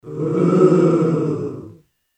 OOO CROWD